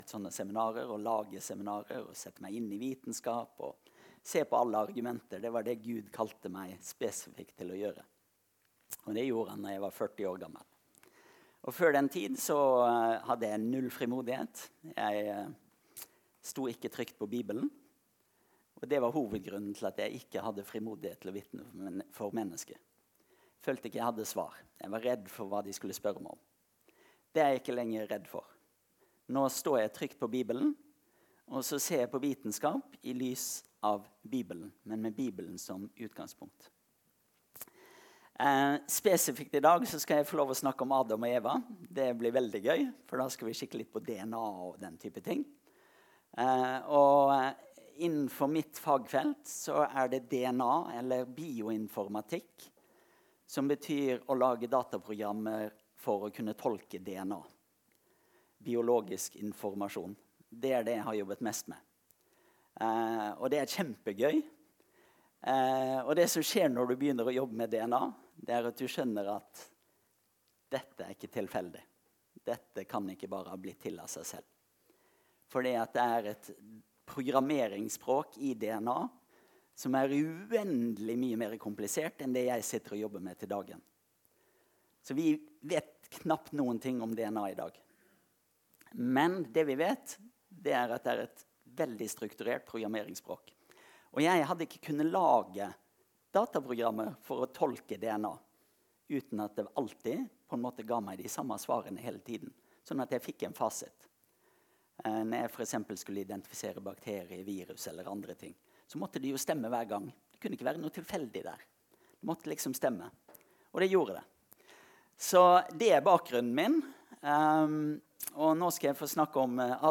Laurhammar bedehus
Møte: Seminar